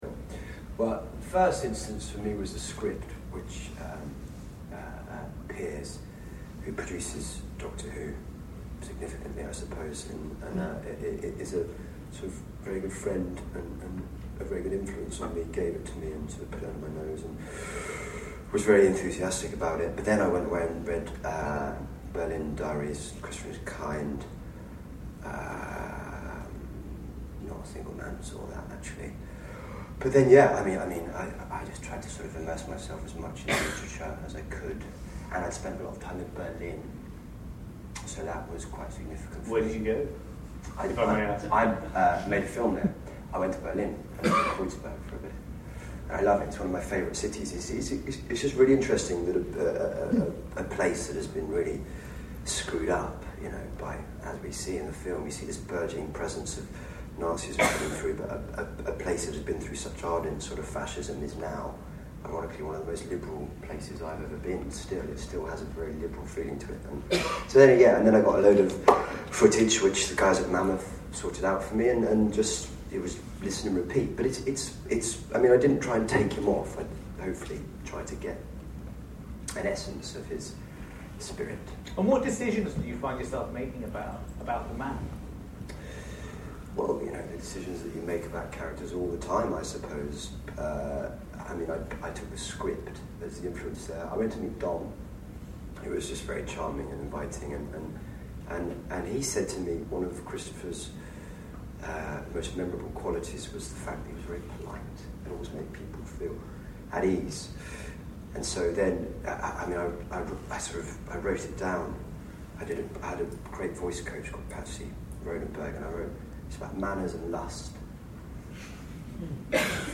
There was a Q&A after the screening with Matt, writer Kevin Elyot and director Geoffrey Sax.
Below are a few short audio extracts of what Matt had to say: